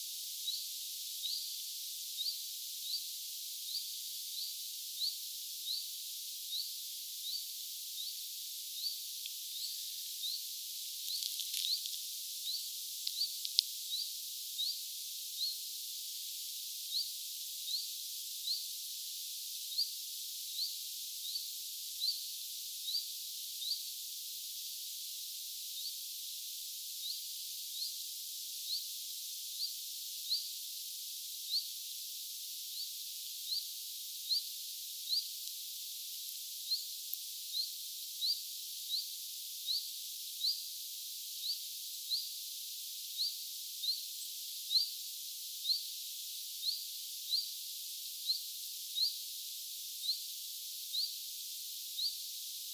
Neljä sellaista muuttotiltalttia kuului tänäänkin.
Tuo bizt-ääni on ilmeisesti todellakin
phylloscopus collybita collybitan ääntelyä.